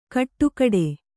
♪ kaṭṭukaḍe